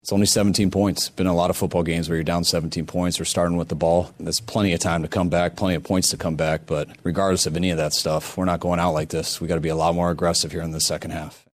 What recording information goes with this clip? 49ers-Post-His-Message-To-Team-During-Halftime.mp3